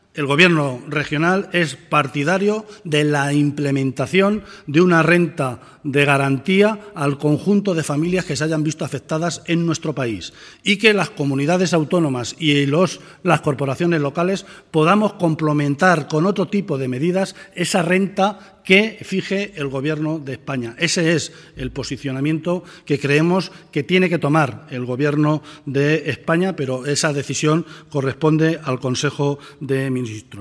• Comparece el vicepresidente del Gobierno regional, José Luis Martínez Guijarro, para informar de  la reunión del Consejo de Gobierno de Castilla-La Mancha
En rueda de prensa tras celebrar el Consejo de Gobierno de la región, Martínez Guijarro ha apuntado que, si a nivel nacional se pone en marcha un sistema orientado a esta garantía de ingresos, las comunidades autónomas y entidades locales podrían complementarla.